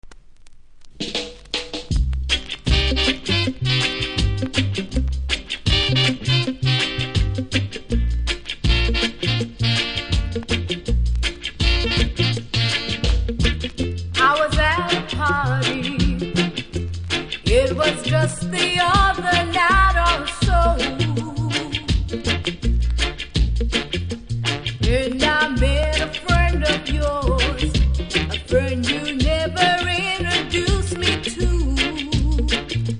所々ノイズありますがプレイは問題無いレベル。
見た目は少しキズ程度、プレス起因のノイズ少し感じます。